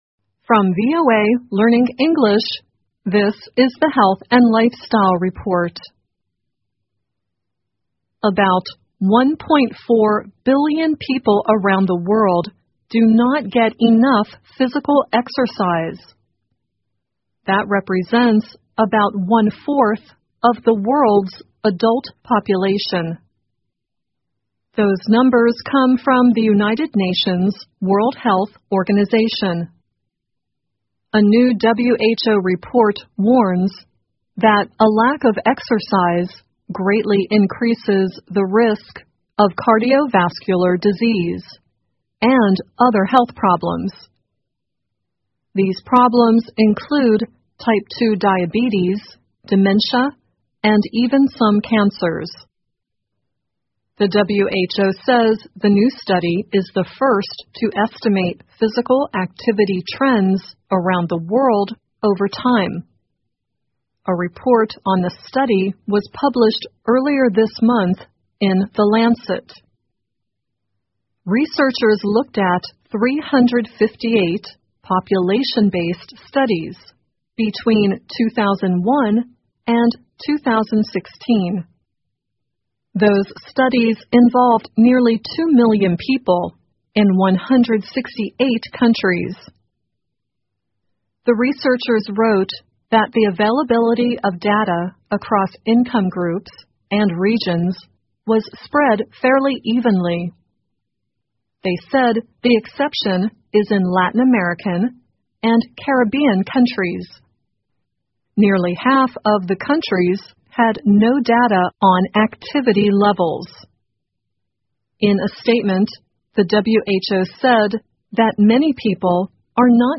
VOA慢速英语2018 研究:运动量不足会导致重大疾病 听力文件下载—在线英语听力室